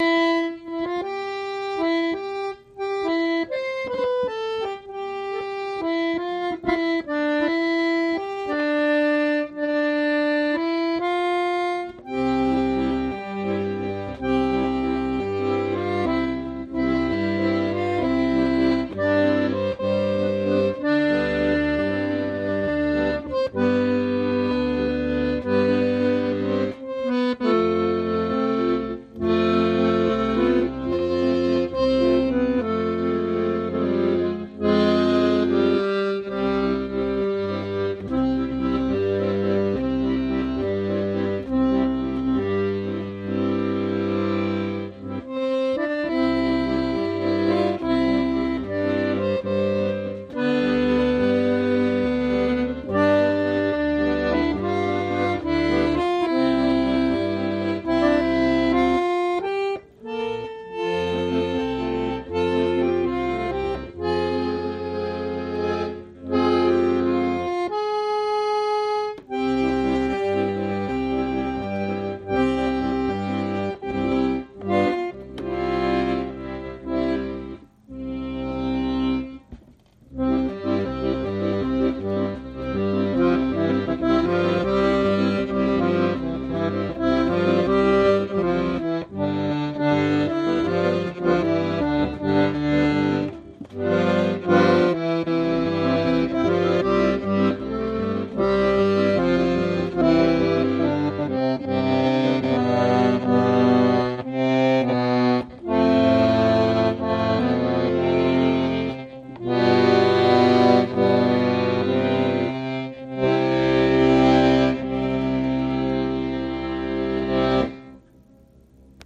You can see how big it is compared to a toy accordion I had for a while ^^ I recorded some audio of me playing some bass lines and just improvising a few things :) - It’s hard to keep up the tempo sometimes, haha. AccordionImprov.mp3